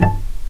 vc_pz-A5-pp.AIF